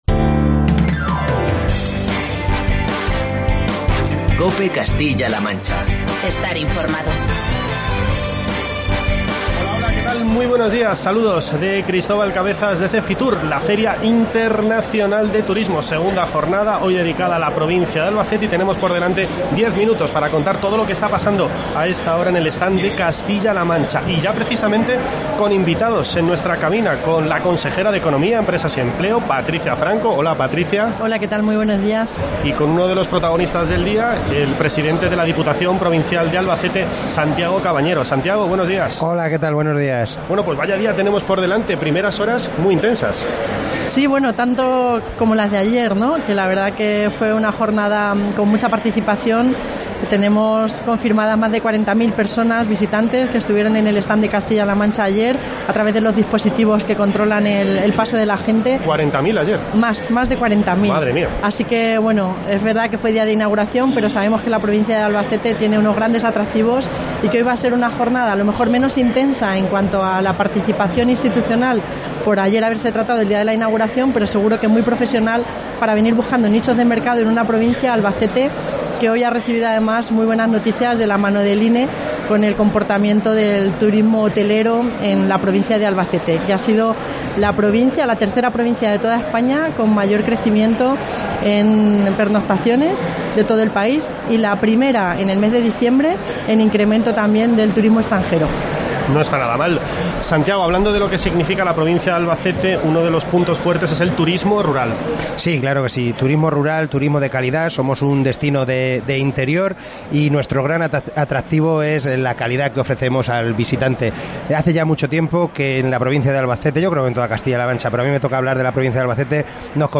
Entrevista con Patricia Franco, consejera de Economía, y con Santiago Cabañero, presidente de la Diputación de Albacete